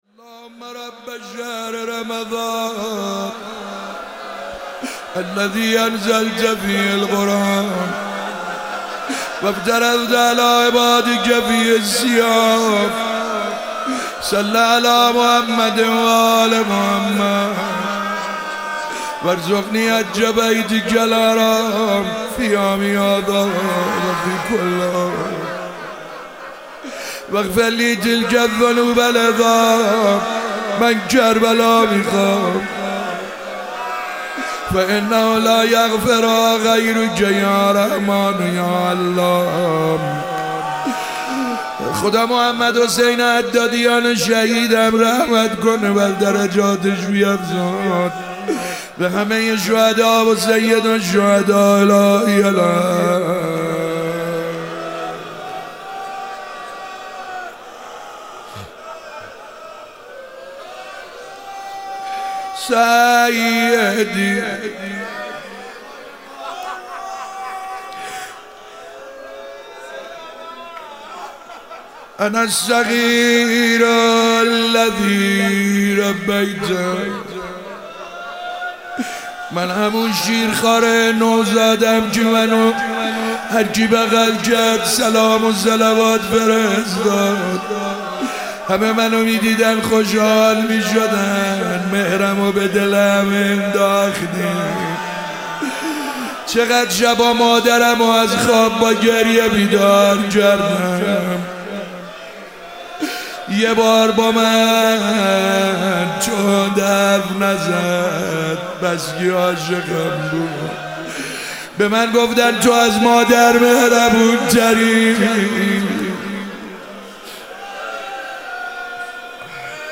شب 19 رمضان 97 - مناجات با خدا